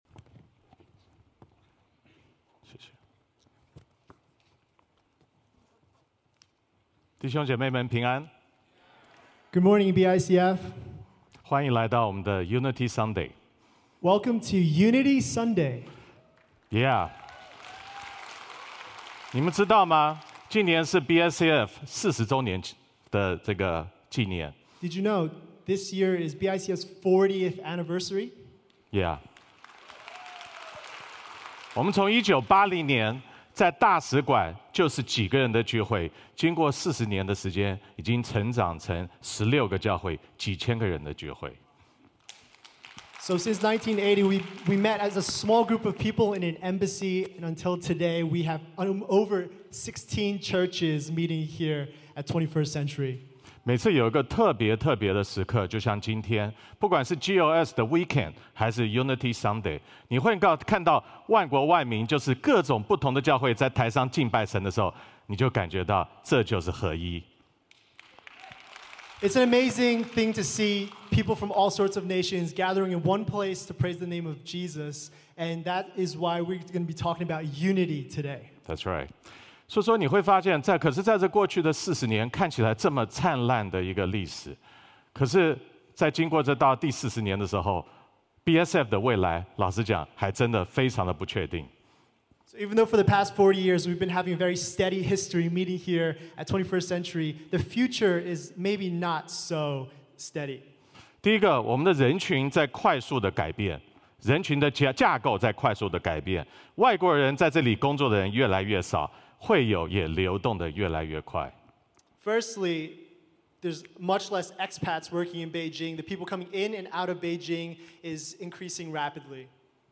主日证道